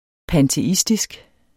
Udtale [ panteˈisdisg ]